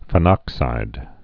(fĭ-nŏksīd)